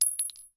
58aea60d01 Divergent / mods / Bullet Shell Sounds / gamedata / sounds / bullet_shells / pistol_generic_5.ogg 13 KiB (Stored with Git LFS) Raw History
pistol_generic_5.ogg